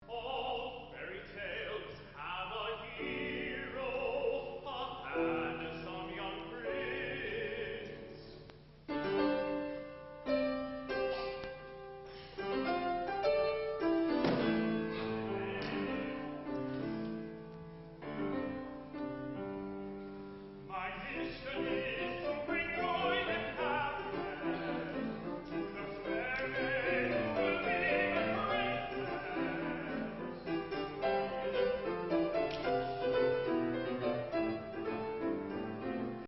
a one-act opera for young audiences